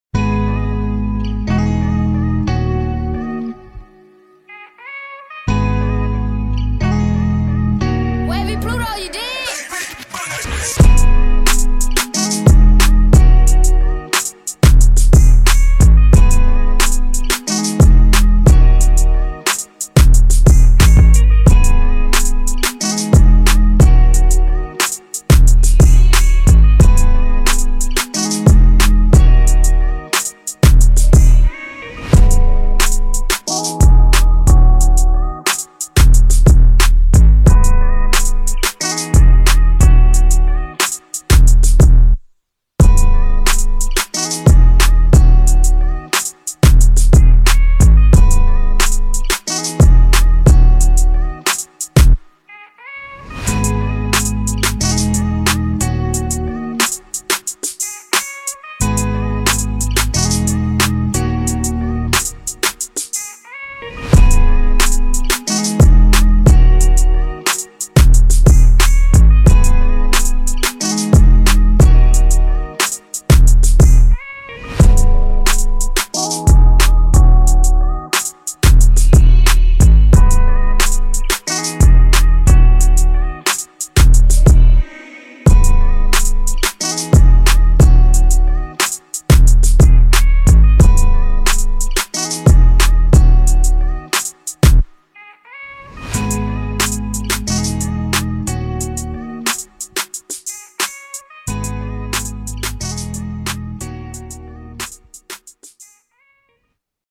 Hip-Hop Instrumentals , Official Instrumentals